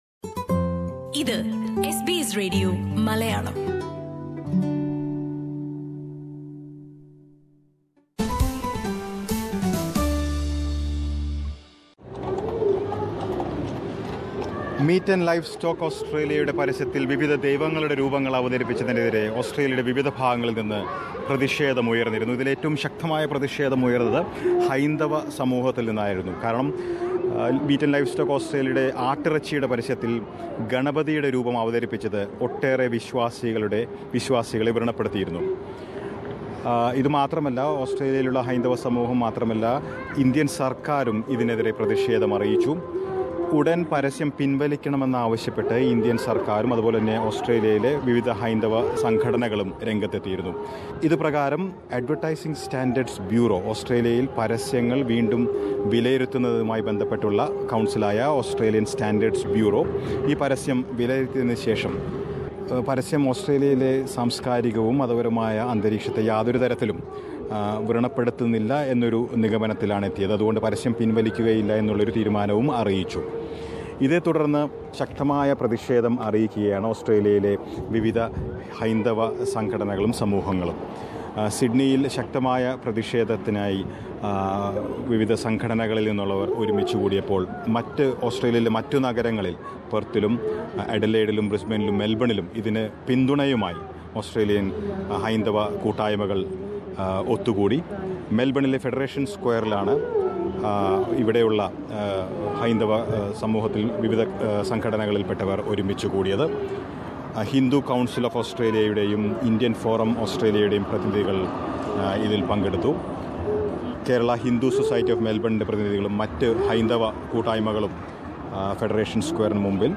Listen to a report from Melbourne's Federation Square about the peaceful gathering of Hindu organisations to protest the controversial lamb meat ad portraying Lord Ganesha.